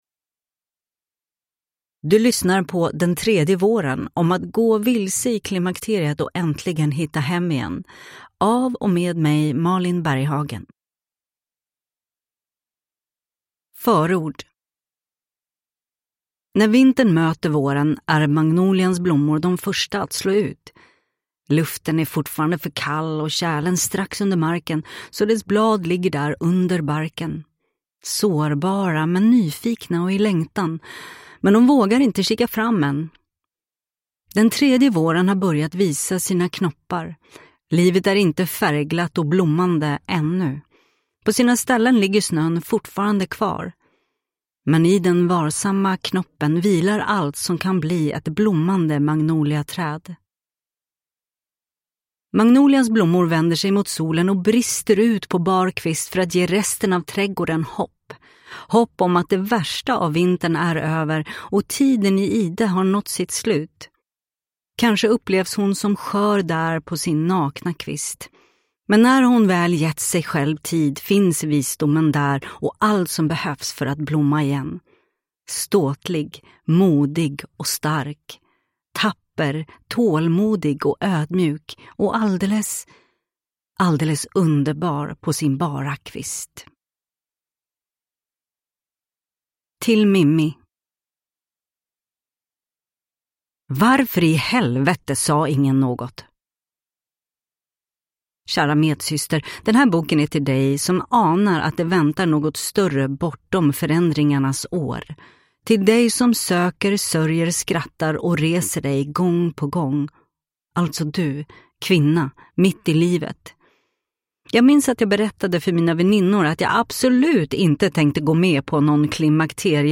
Den tredje våren – Ljudbok
Uppläsare: Malin Berghagen